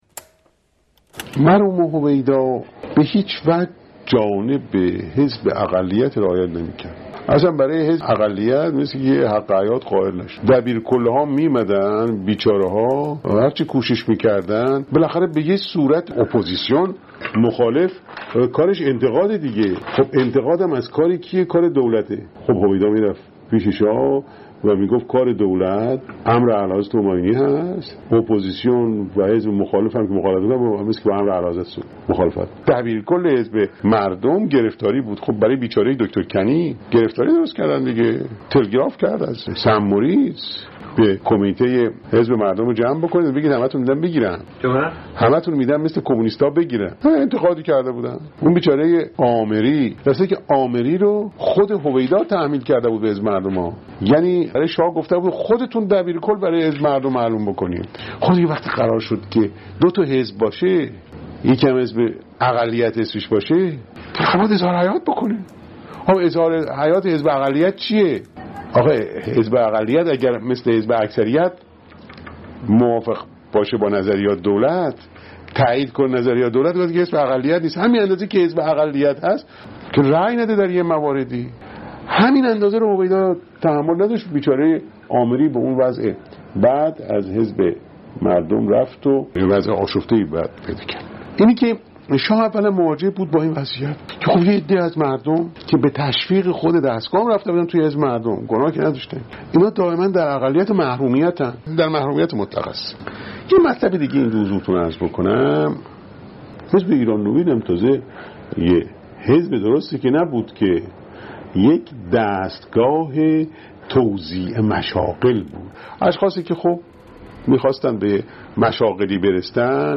مجموعه مستند «ملاقات با تاریخ» که در شبکه مستند تهیه و تولید شده، برداشتی از پروژه تاریخ شفاهی ایران است، خبرگزاری ایکنا به مناسبت دهه فجر مجموعه‌ای از این مستند را در قالب پادکست تهیه کرده است که دومین قسمت آن را با خاطره معتضد باهری از حزب اقلیت و اکثریت می‌شنوید.